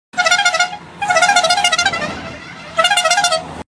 Sons - Effets Sonores